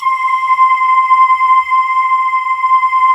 Index of /90_sSampleCDs/USB Soundscan vol.28 - Choir Acoustic & Synth [AKAI] 1CD/Partition D/03-PANKALE